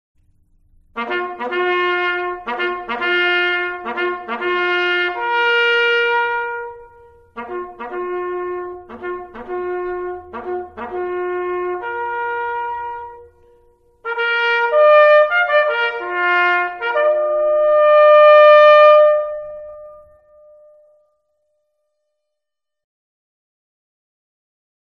Remembrance Day
Rouse (Reveille)